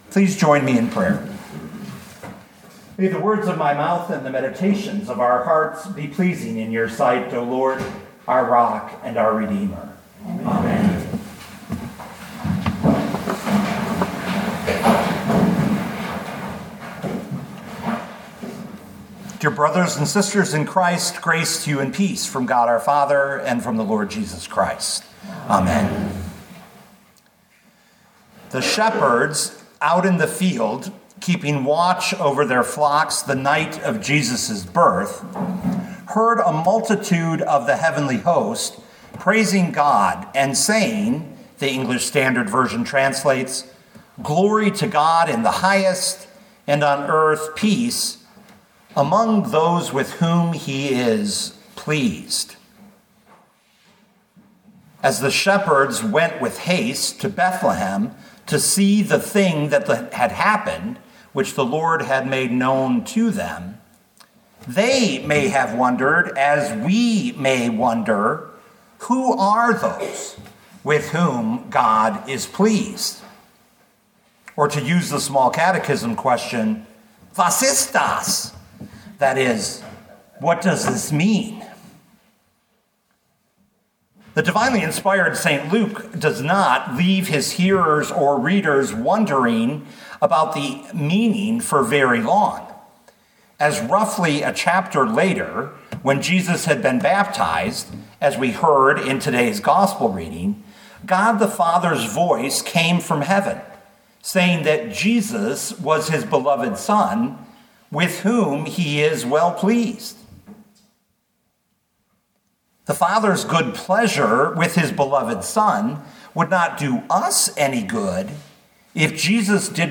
2025 Luke 3:21-22 Listen to the sermon with the player below, or, download the audio.